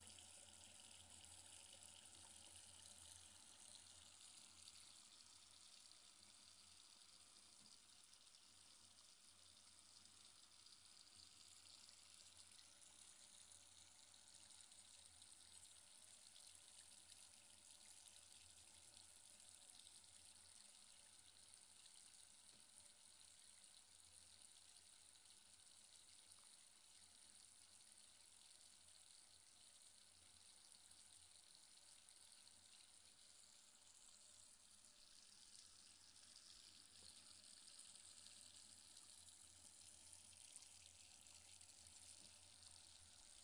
描述：这是拉链打开和关闭的声音
标签： 拉链 clothin克 浴室